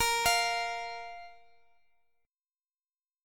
Listen to A#5 strummed